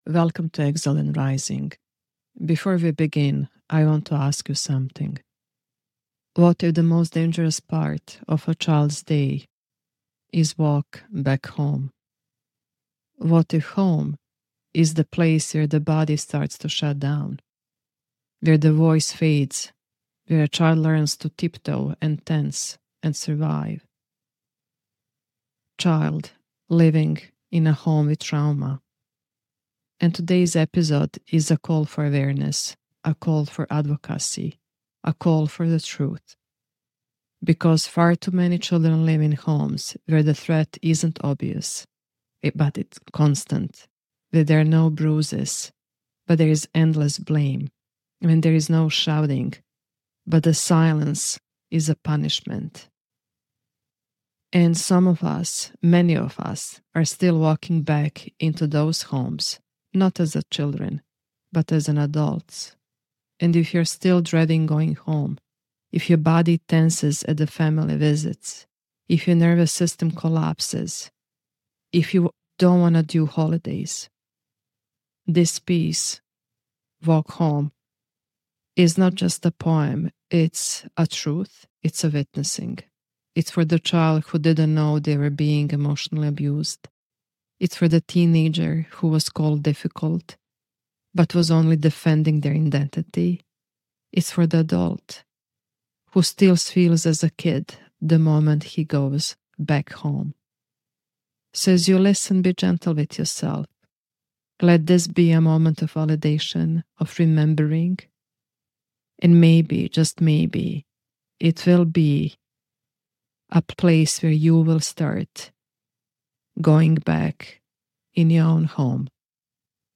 This episode is a somatic witnessing, not an intellectual unpacking.
No production teams. Fancy edits. Only a truth storytelling.